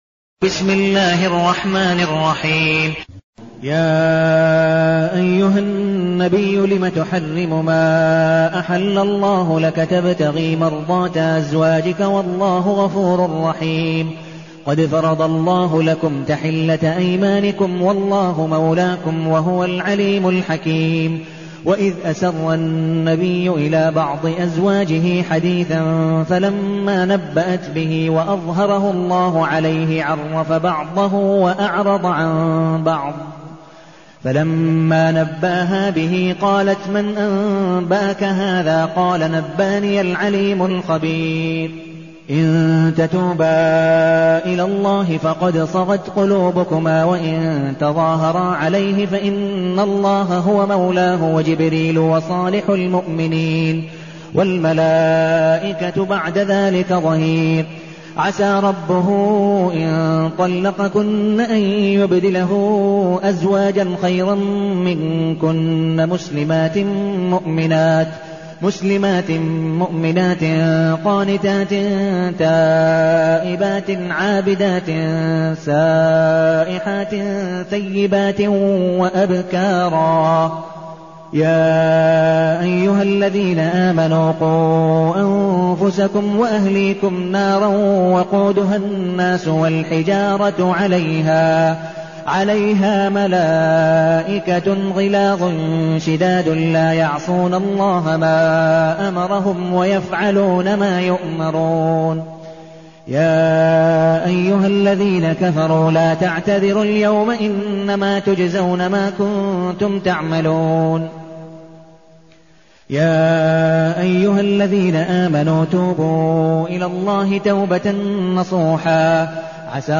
المكان: المسجد النبوي الشيخ: عبدالودود بن مقبول حنيف عبدالودود بن مقبول حنيف التحريم